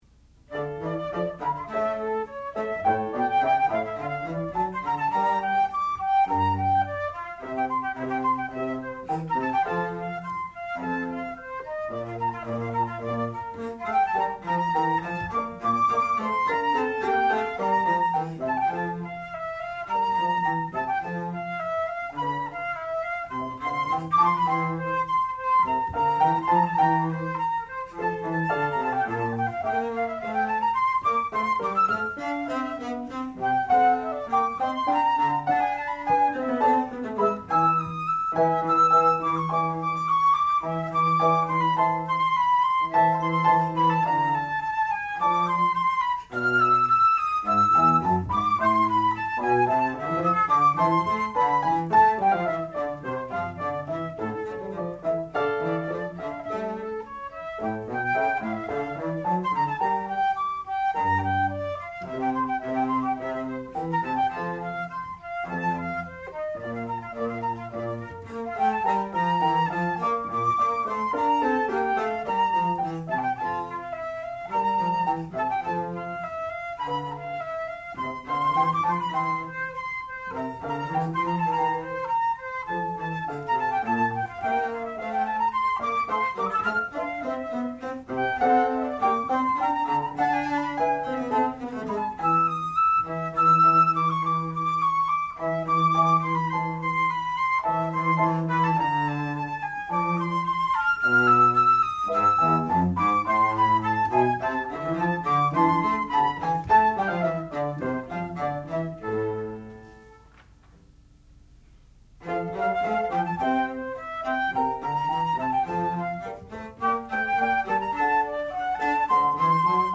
sound recording-musical
classical music
violoncello
piano
Qualifying Recital